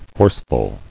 [force·ful]